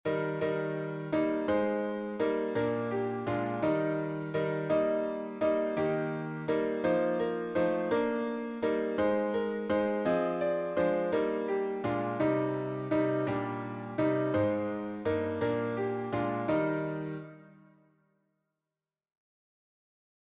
Traditional English melody